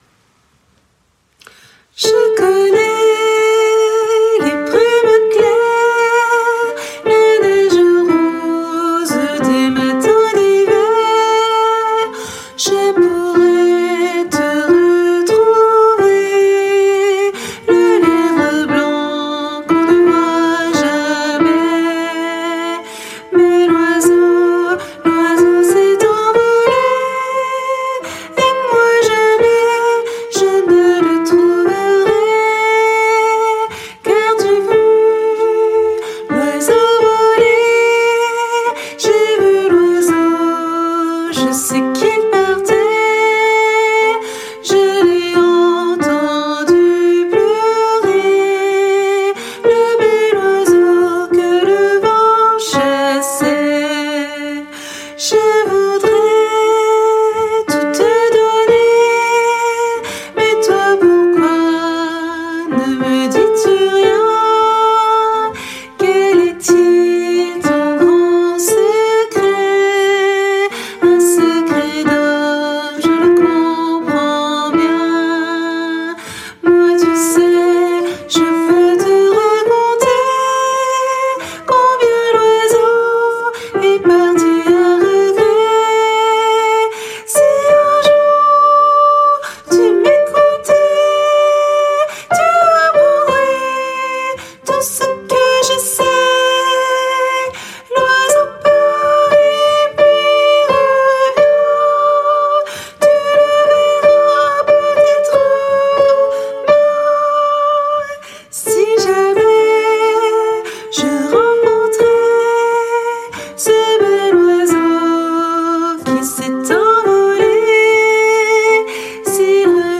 - Oeuvre pour choeur à 4 voix mixtes (SATB)
MP3 versions chantées
Alto et autres voix en arrière-plan